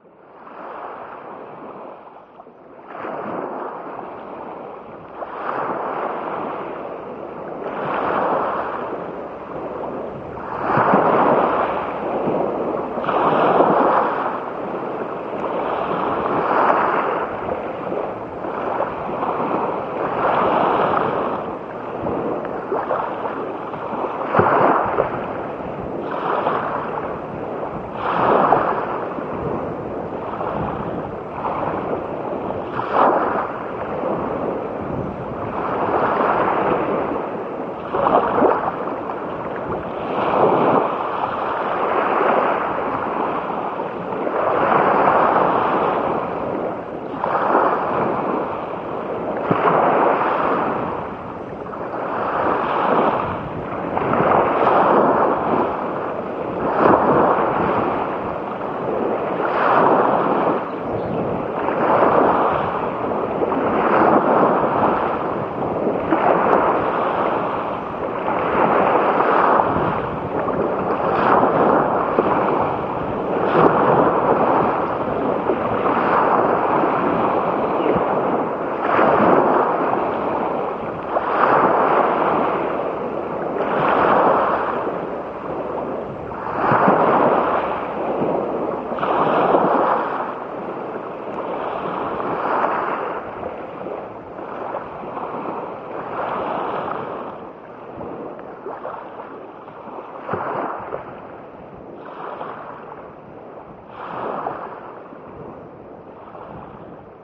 eine Geräuschinstalation